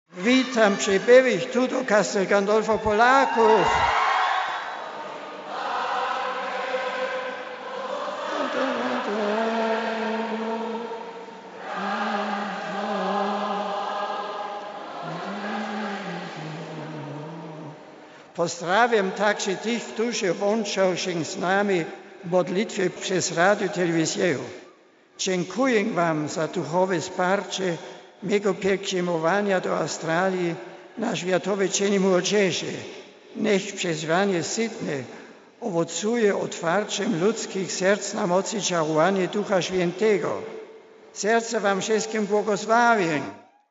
Słuchaj Papieża po polsku: RealAudio